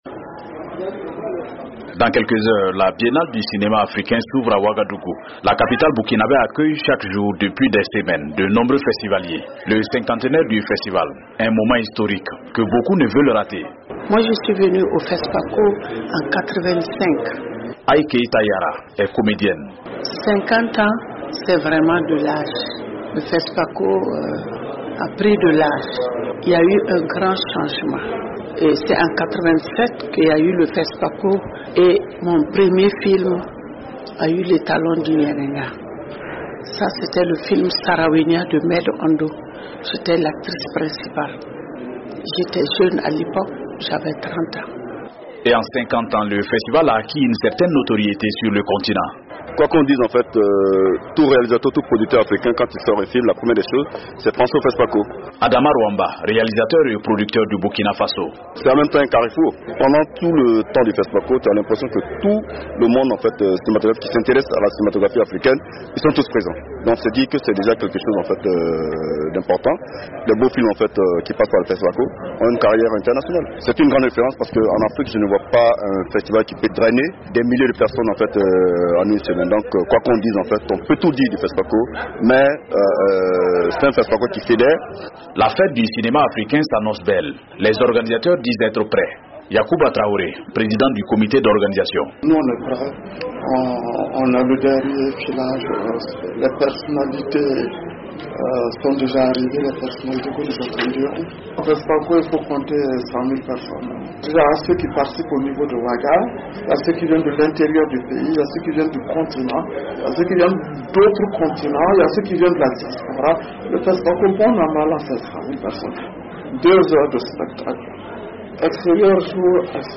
La 26e édition du Fespaco, festival panafricain du cinéma et de la télévision de Ouagadougou s’ouvre demain dans la capitale burkinabè. Tout est fin prêt selon les organisateurs à accueillir le festival qui célèbre ses 50 ans. De Ouagadougou, notre correspondant